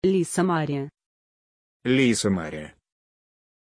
Pronunciation of Lisamarie
pronunciation-lisamarie-ru.mp3